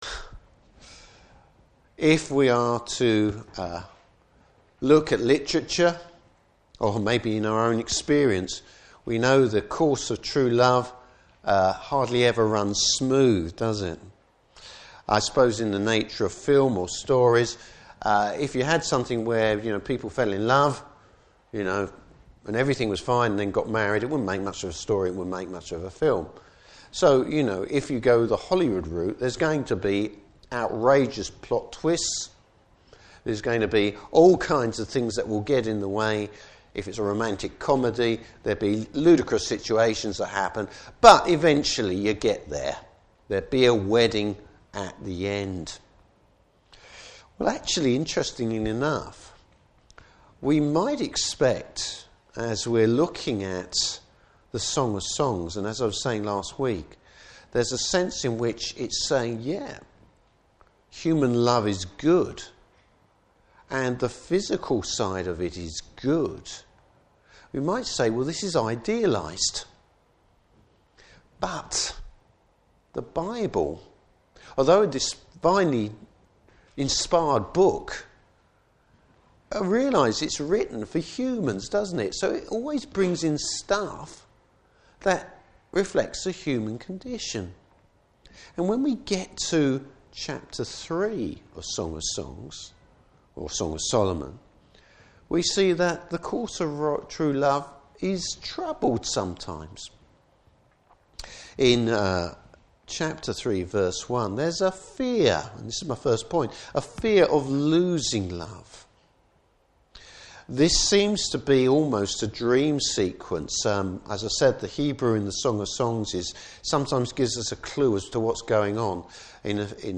Service Type: Morning Service Bible Text: Song of Solomon 3:1-5:1.